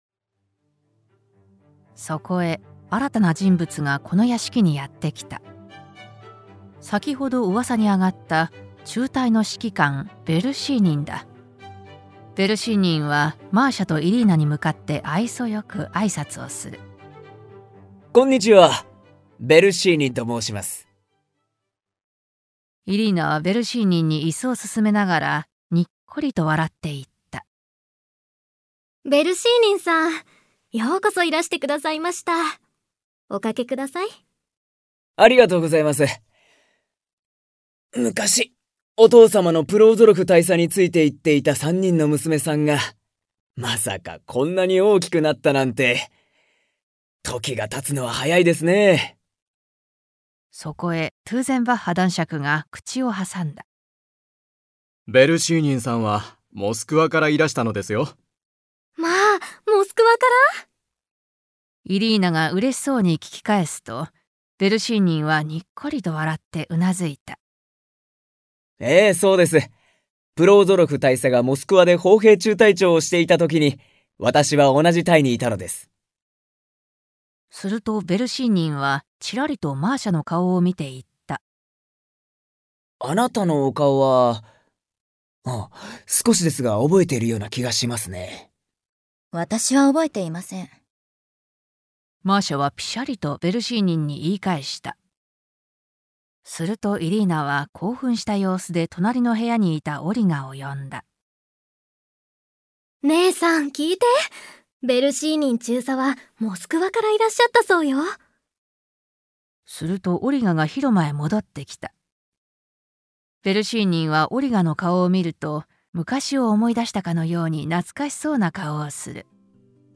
[オーディオブック] 三人姉妹（こどものための聴く名作 39）